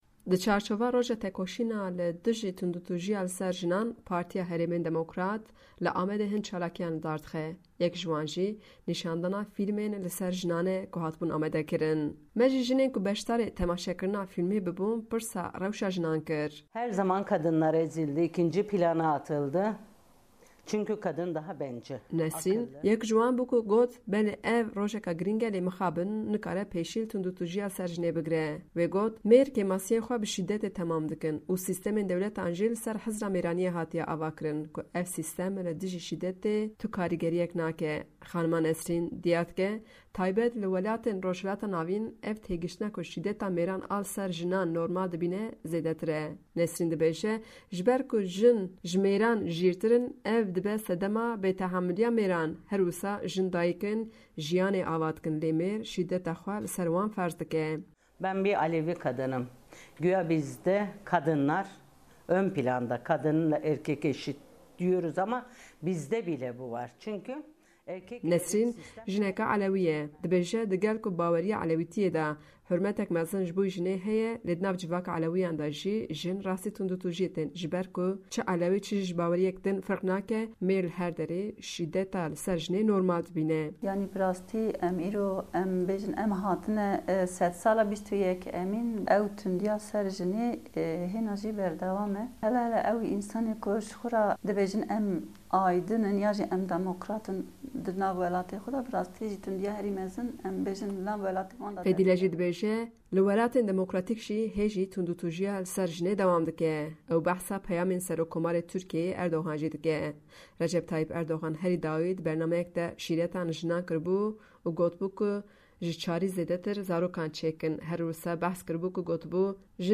Me ji jinên ku beşdarî temaşekirina fîlm bibûn, pirsa rewşa jinan kir.